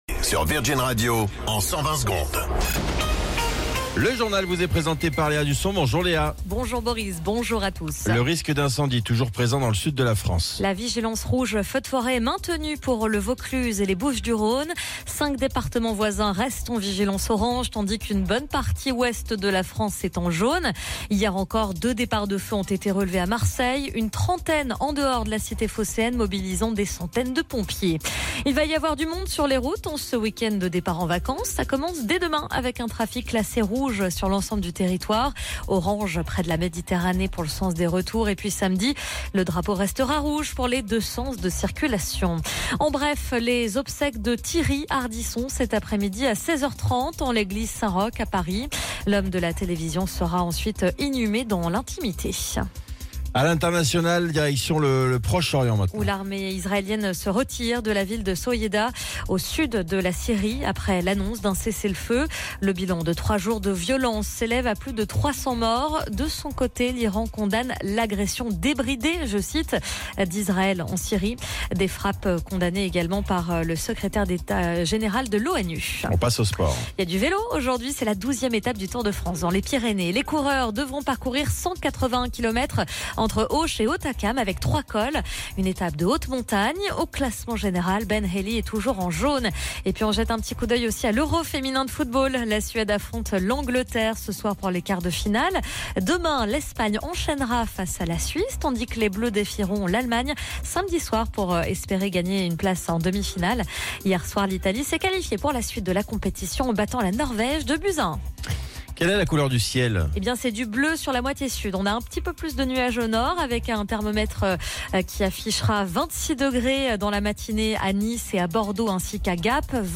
Flash Info National 17 Juillet 2025 Du 17/07/2025 à 07h10 .